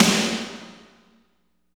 52.09 SNR.wav